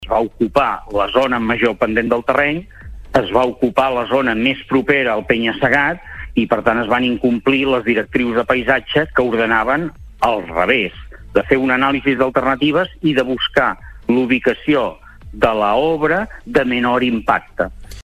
En una entrevista al Supermatí de Ràdio Capital